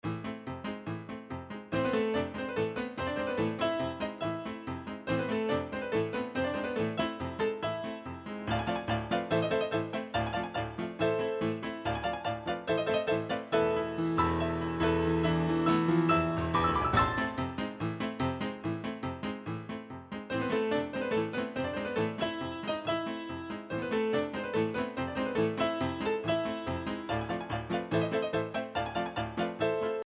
Voicing: 2 Piano 4 Hands